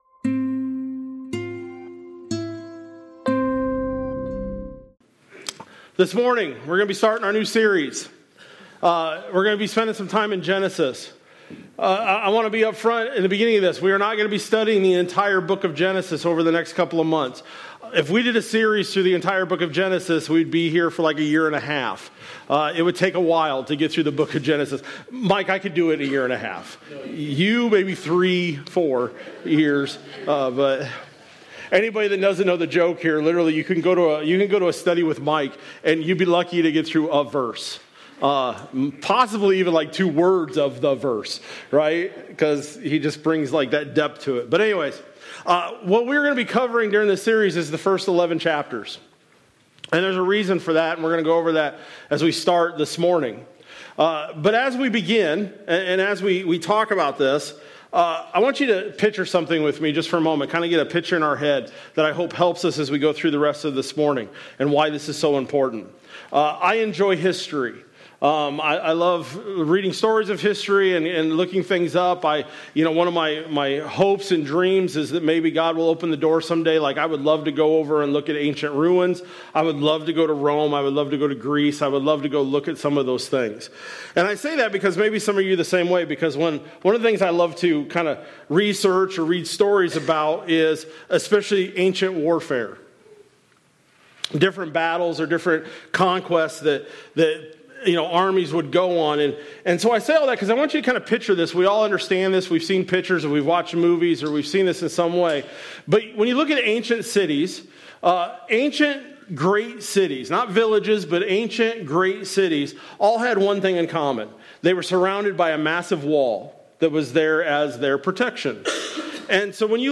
Jan-11-26-Sermon-Audio.mp3